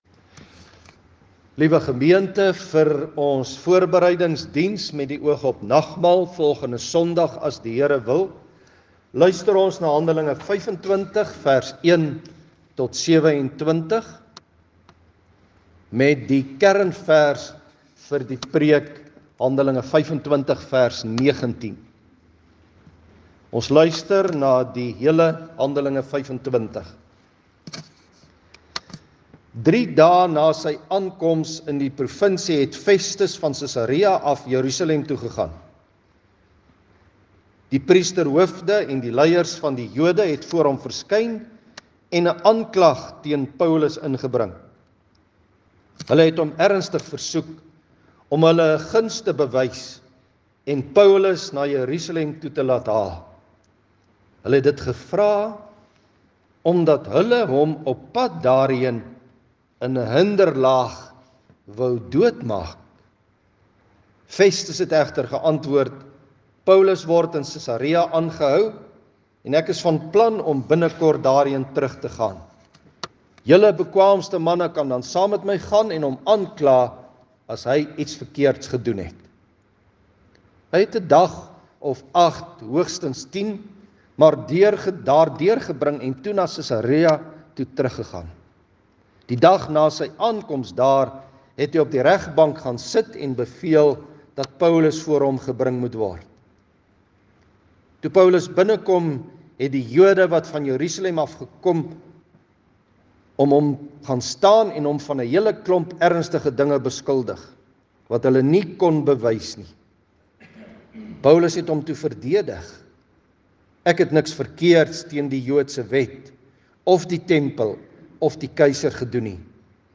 Vanoggend is ons Voorbereidingsdiens.